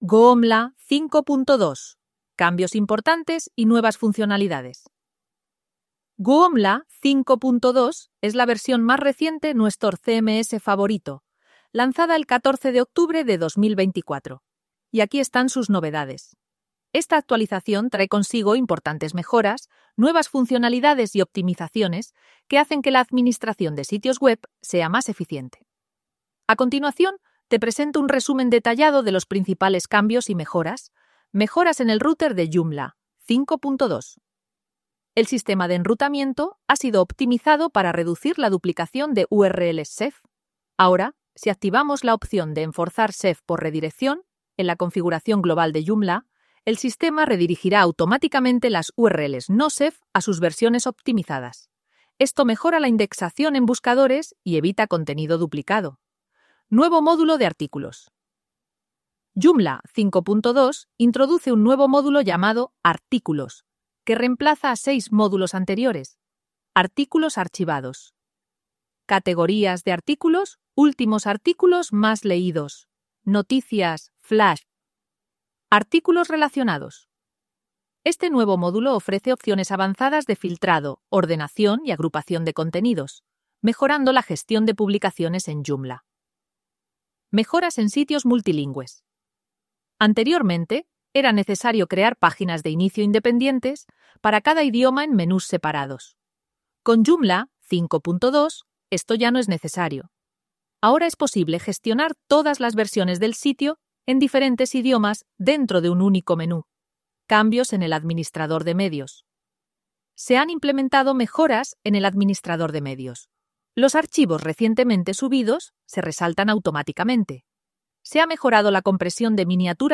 Podcast sobre Joomla en español con JoomlIA Robers, una IA